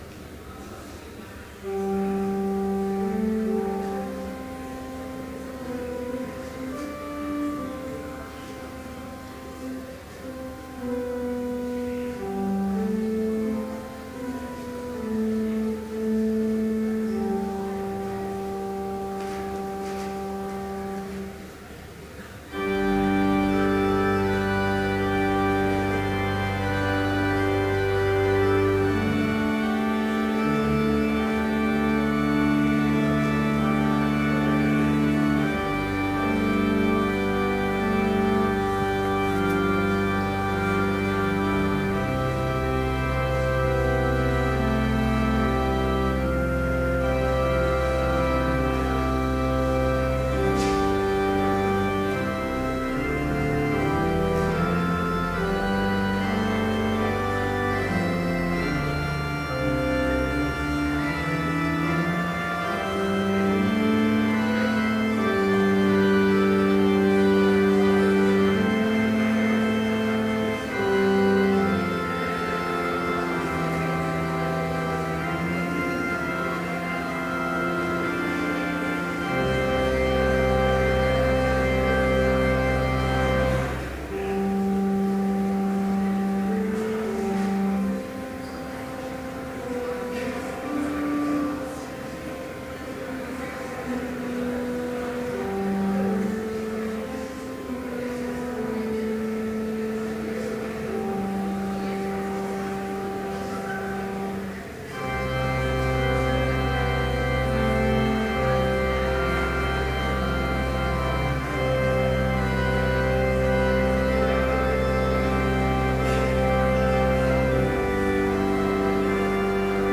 Complete service audio for Chapel - October 9, 2012